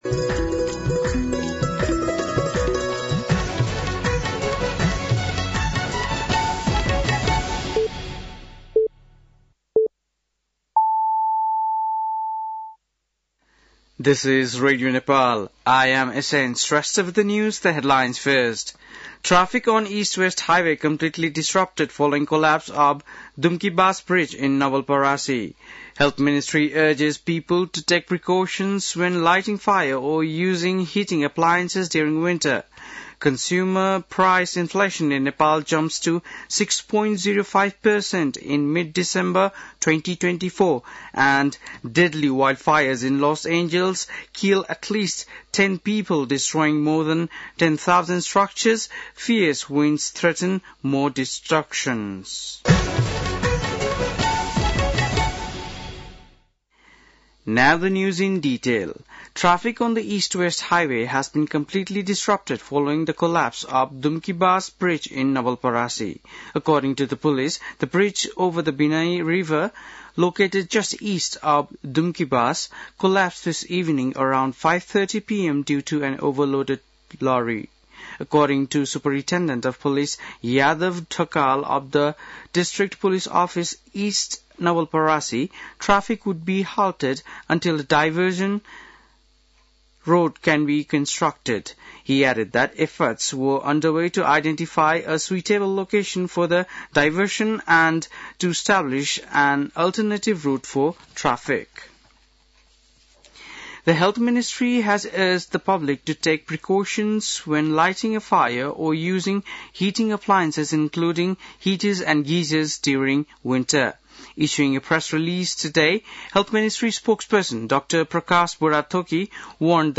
बेलुकी ८ बजेको अङ्ग्रेजी समाचार : २७ पुष , २०८१